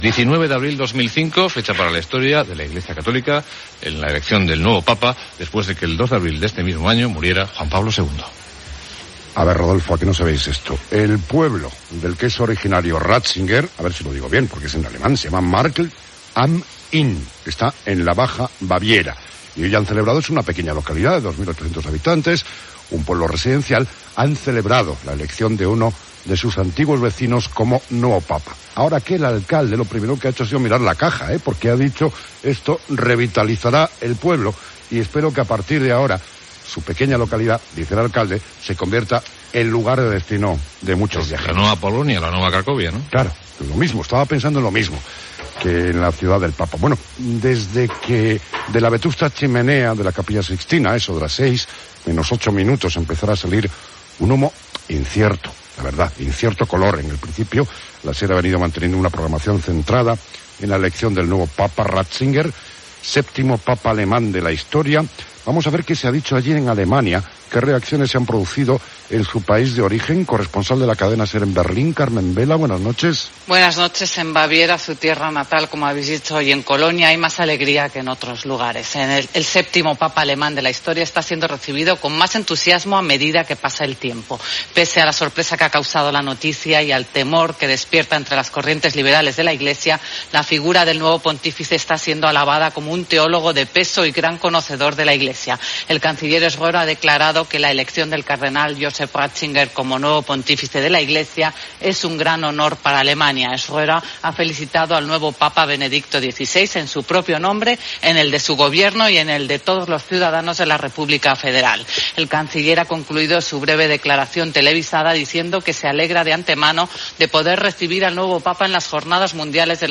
23ce95389cef0e577f83d2d1f8c03027e660554e.mp3 Títol Cadena SER Emissora Ràdio Barcelona Cadena SER Titularitat Privada estatal Descripció Informatiu especial sobre l'elecciò del cardenal Joseph Ratzinger com a nou Sant Pare, amb el nom de Benet XVI.